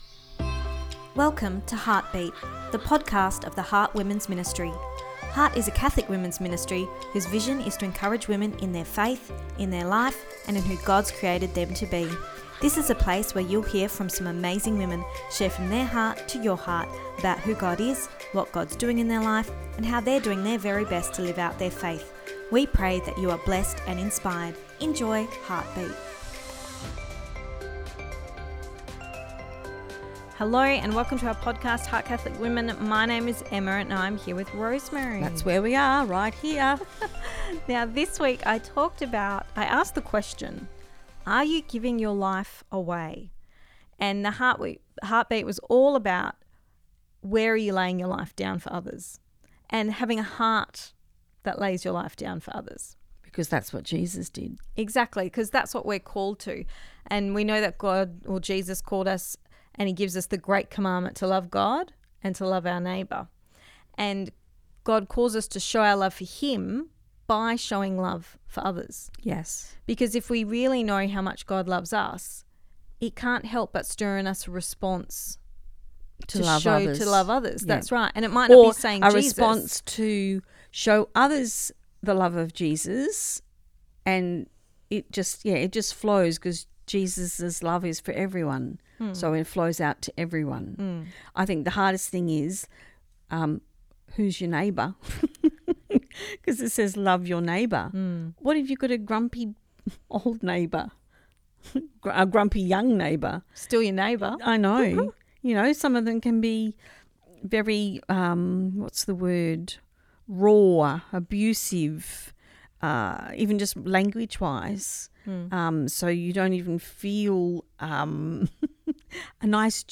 Ep245 Pt2 (Our Chat) – Are You Giving Your Life Away?